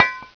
bounce.wav